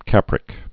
(kăprĭk)